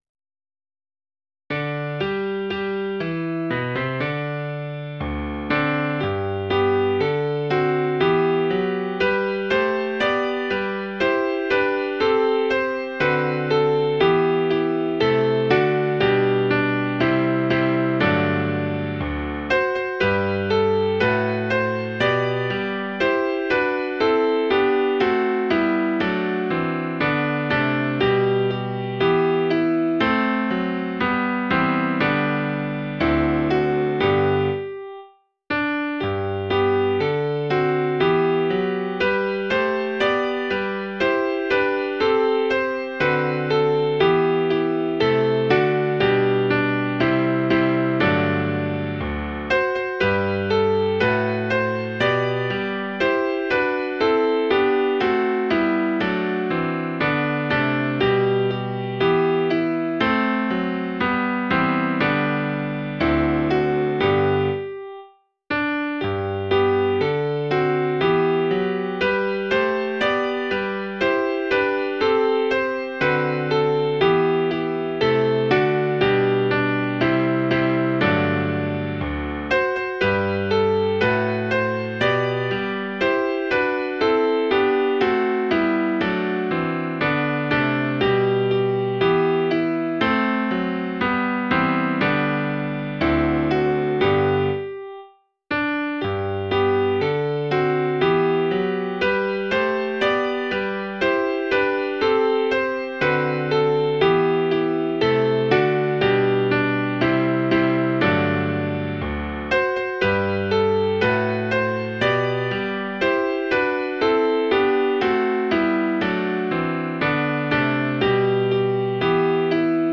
abenbow.mid.ogg